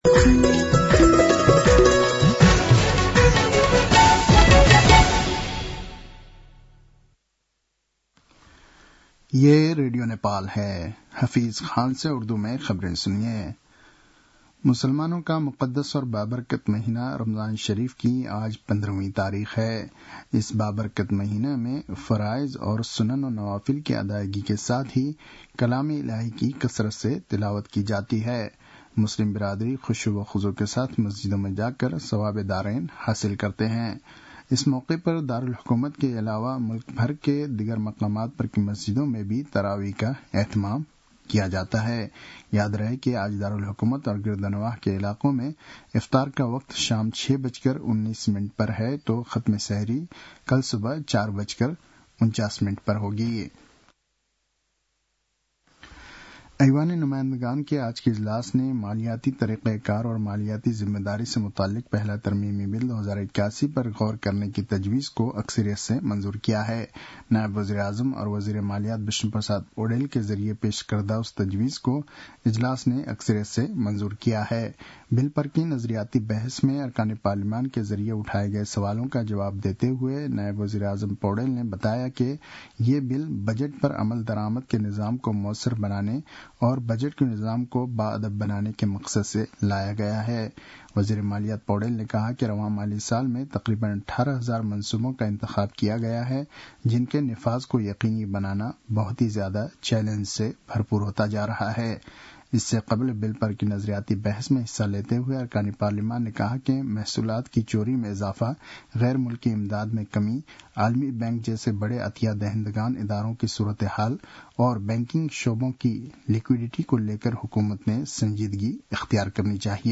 An online outlet of Nepal's national radio broadcaster
उर्दु भाषामा समाचार : ३ चैत , २०८१